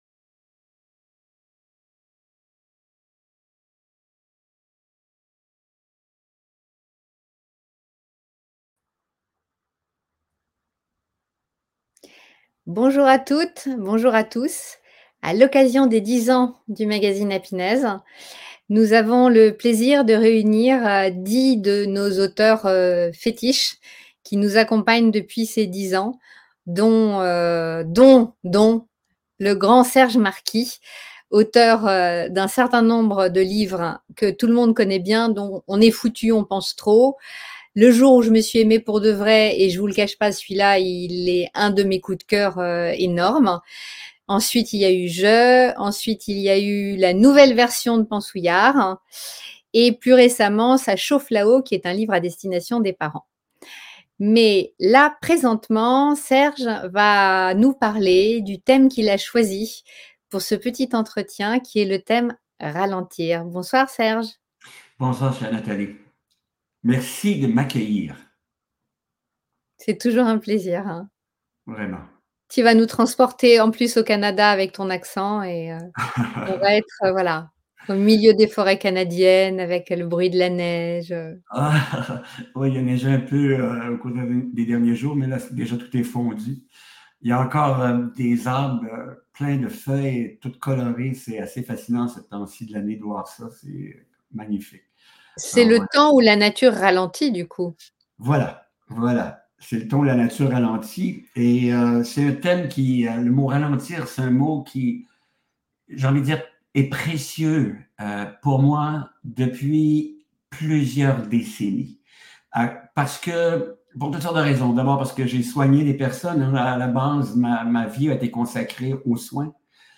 Interview 10 ans - Ralentir